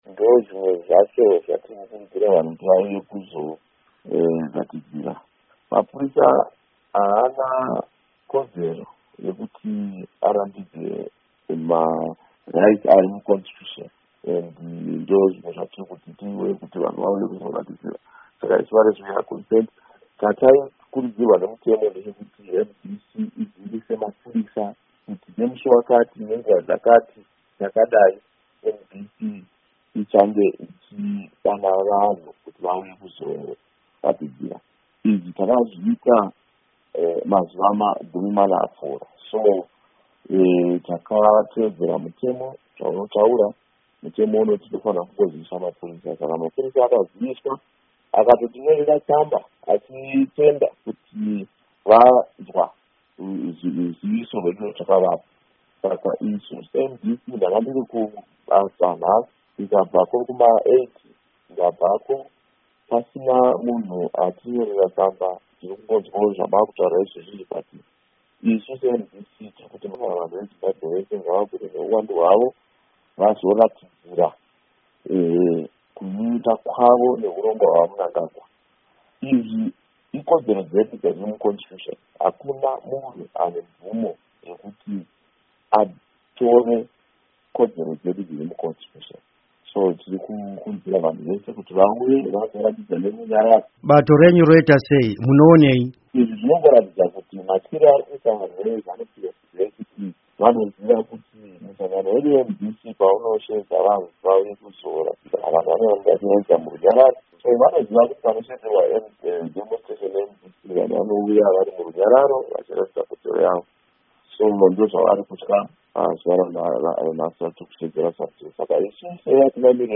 Hurukuro naVaCharlton Hwende